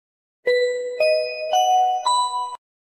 PLAY Paging Intro
paging-intro.mp3